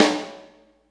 snare13.mp3